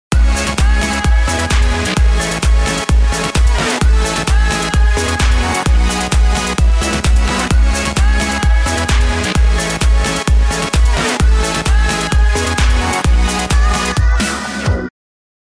3回繰り返して4回目で変化させる
Endless Streets Vox Atmospheres 02には、Ah〜のあとに続くメロディがあるのですが、最初の3回はそこをカットして、サビ前の4回目のときにその部分を付け加えます。